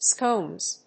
/skonz(米国英語), skəʊnz(英国英語)/